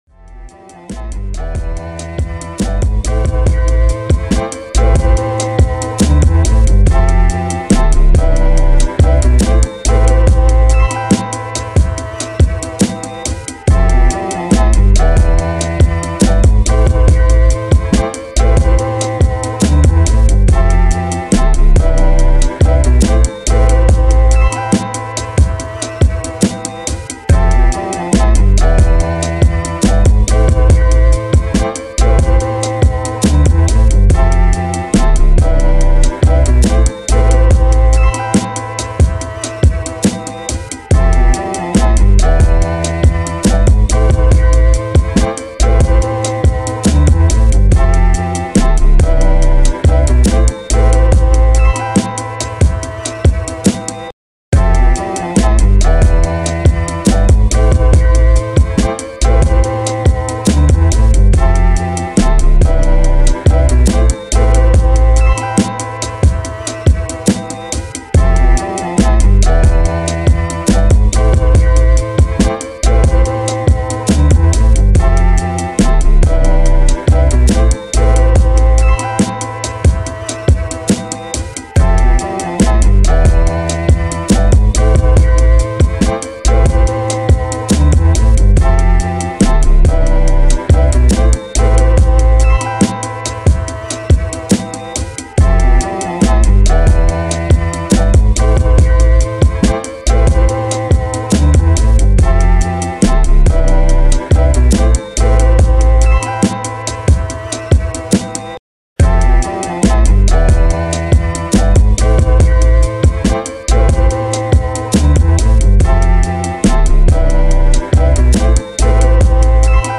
Listen and share this new hip hop instrumental.
official beat remake